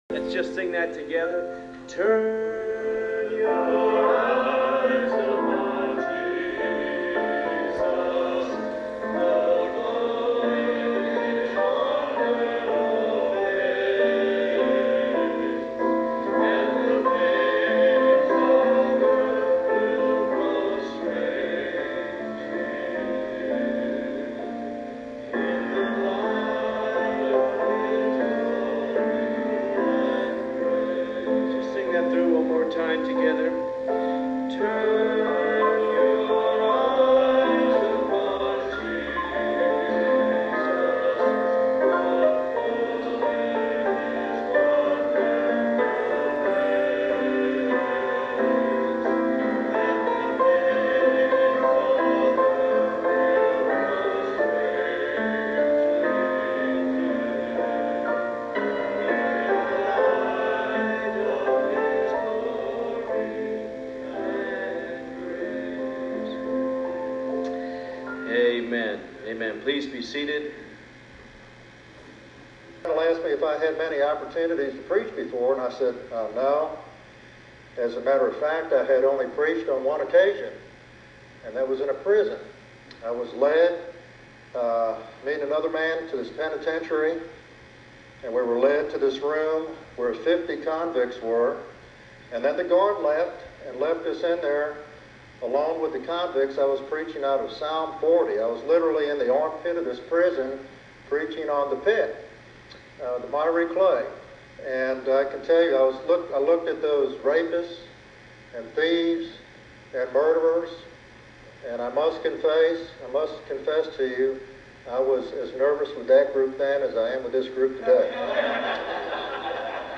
• Introduction with a familiar worship song
The sermon is based on Luke chapter 10.